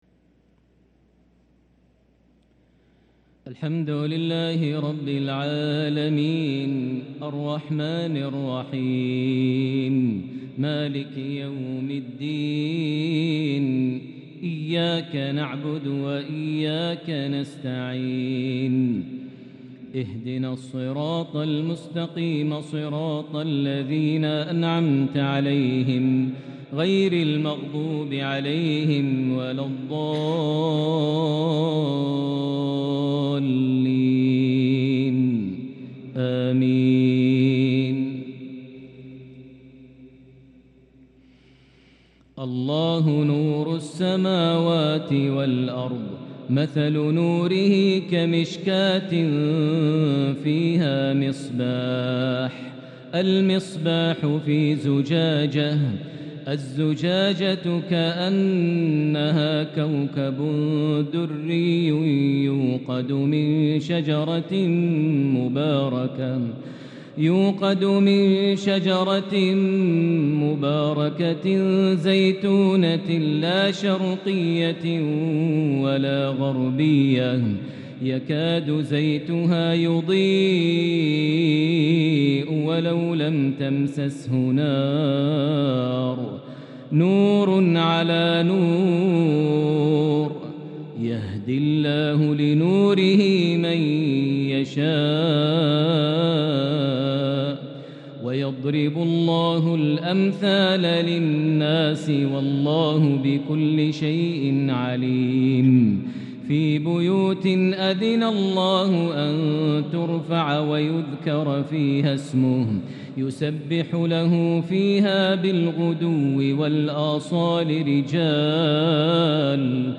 تلاوة بديعة من سورة النور 35-44 | عشاء السبت 2-5-1444هـ > 1444 هـ > الفروض - تلاوات ماهر المعيقلي